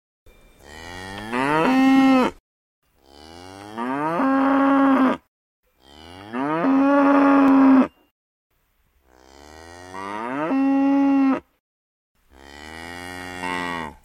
Cow Moos 76219 (audio/mpeg)